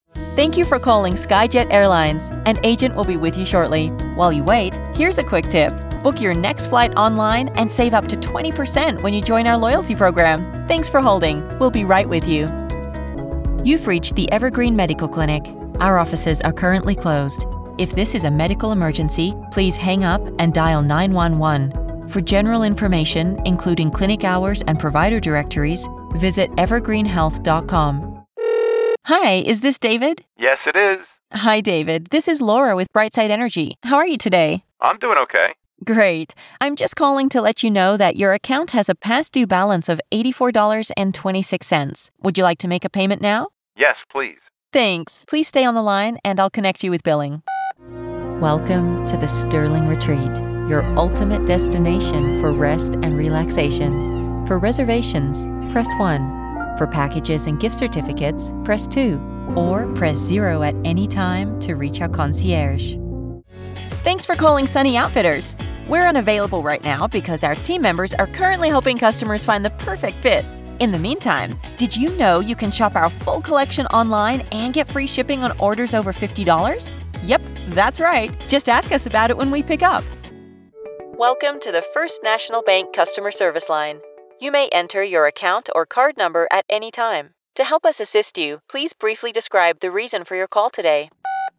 Female
I'm a trained actor with a grounded, connected sound.
Phone Greetings / On Hold
Perfectly Human Prompts & Moh!
Words that describe my voice are Relatable, Conversational, Versatile.